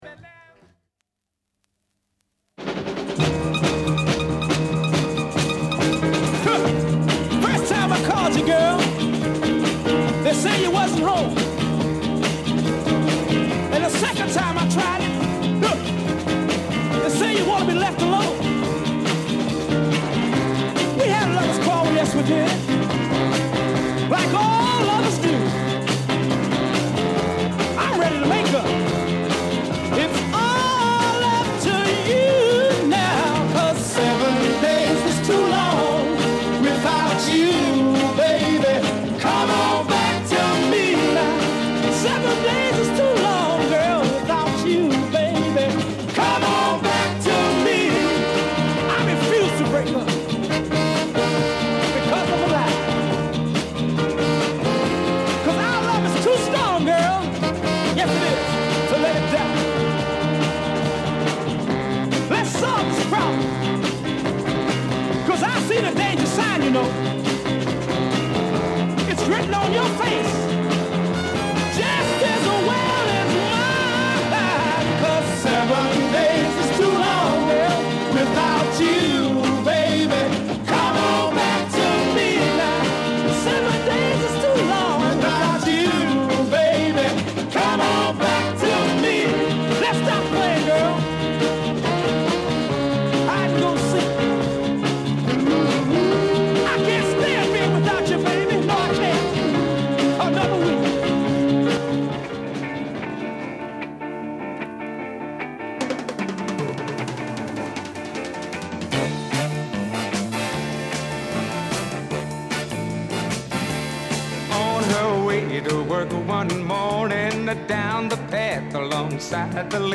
il Rocksteady,lo Ska,il BlueBeat